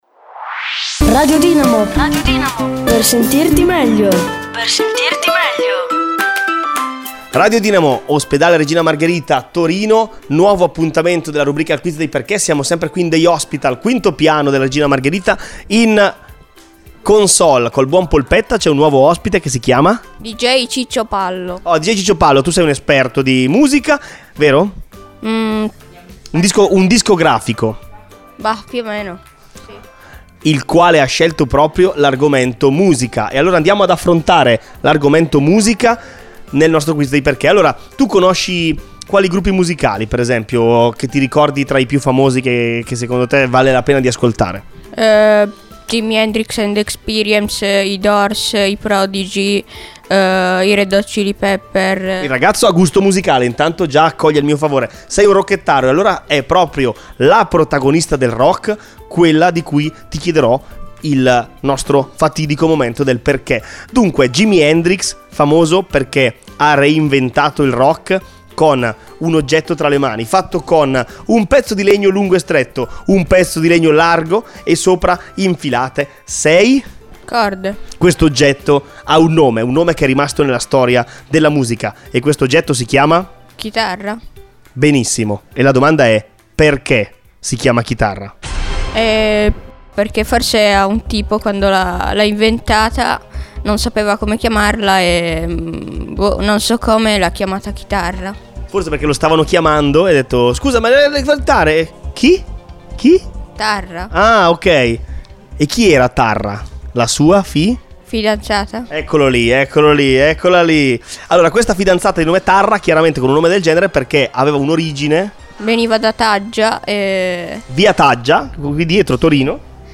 LA DIRETTA!!!
Regina Margherita, Torino.